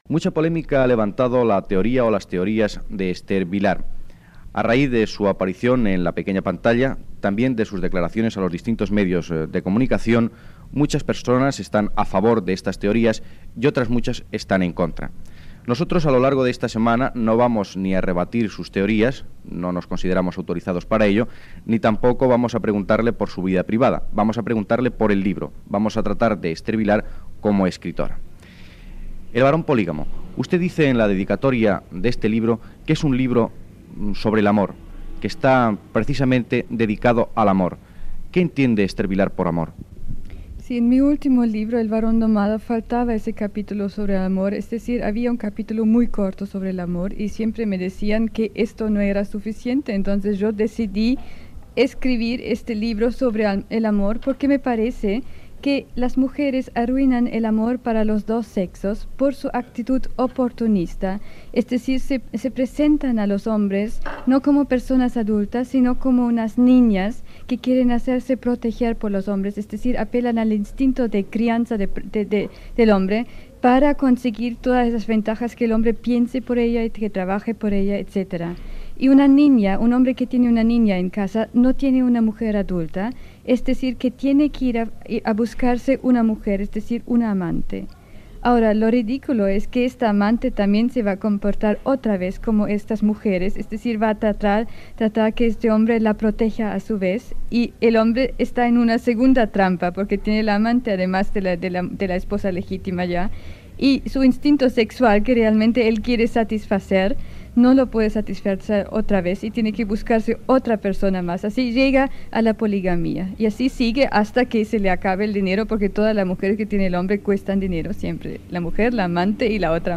Secció "Oficio de escritor". Primera part de l'entrevista a l'escriptora Esther Vilar, autora del llibre "El varón polígamo"
Informatiu